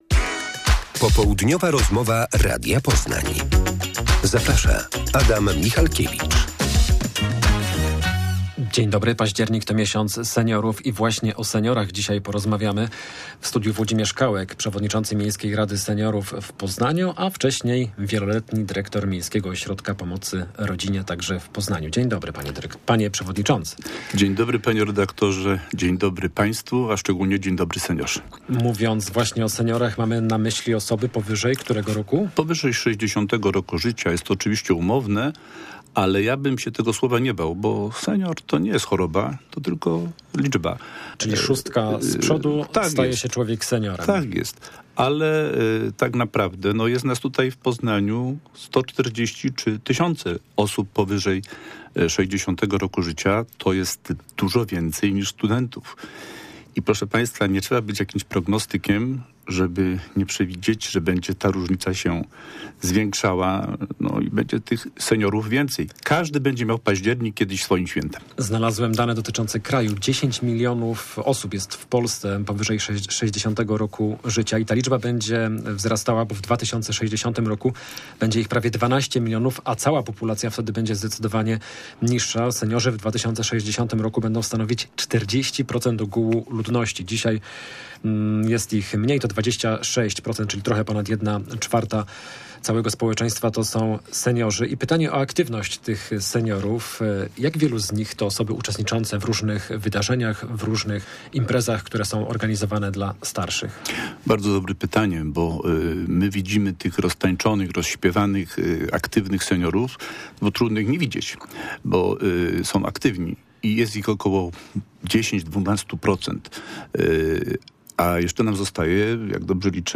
Popołudniowa rozmowa